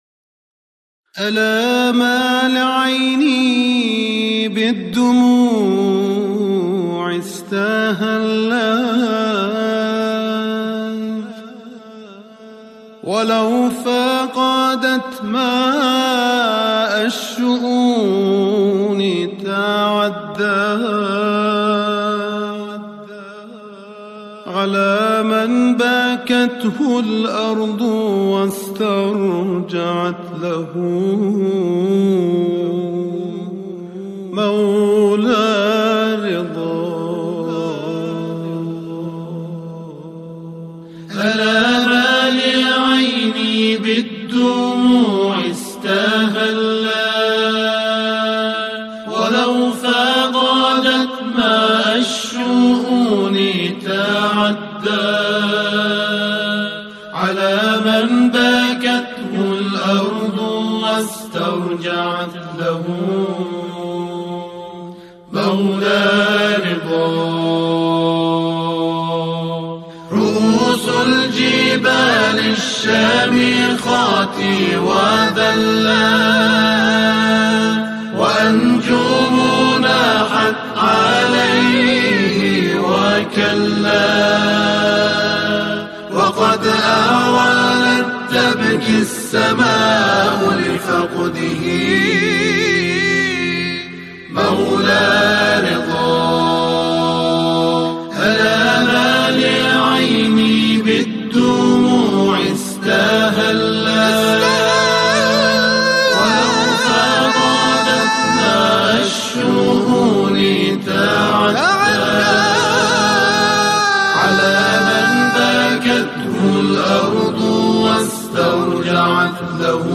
سرودهای امام رضا علیه السلام